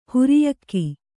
♪ huriyakki